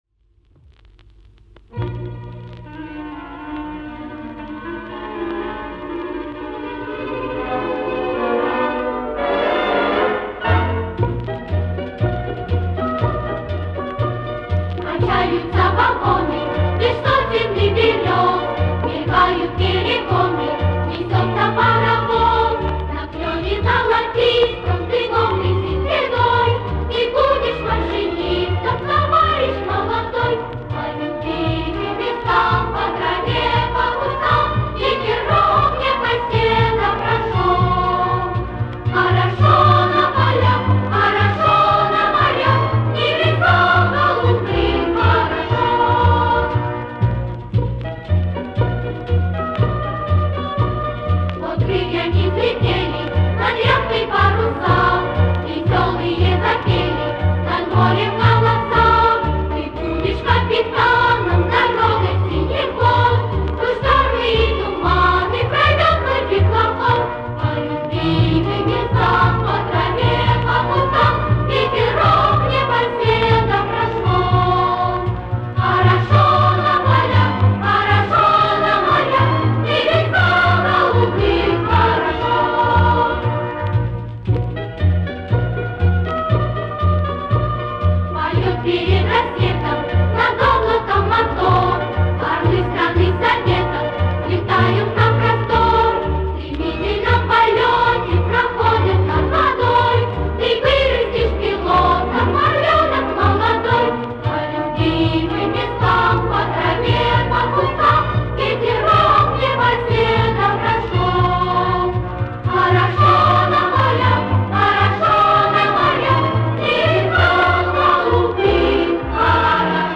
Послевоенный вариант исполнения.